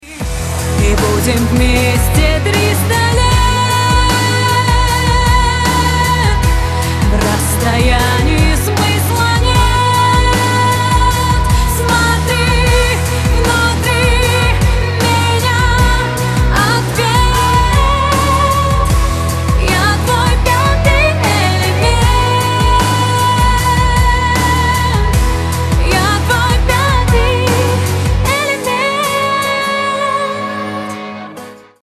• Качество: 256, Stereo
поп
женский вокал